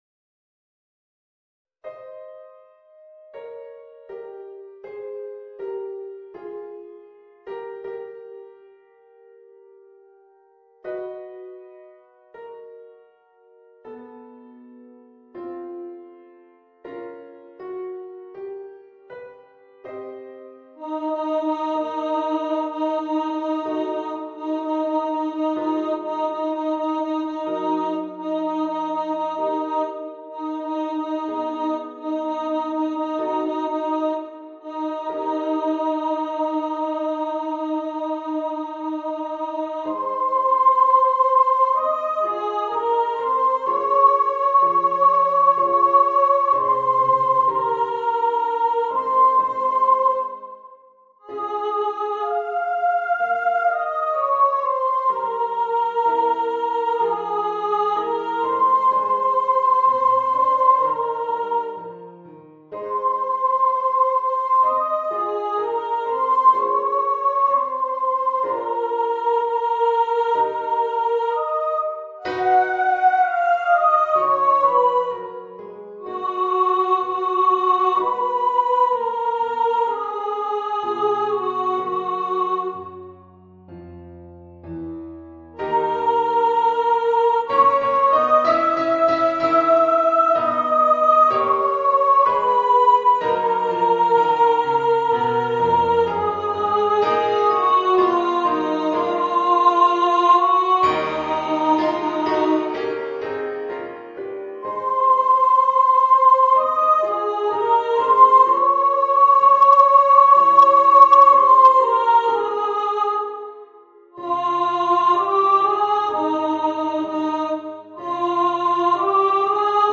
Voicing: Voice and Piano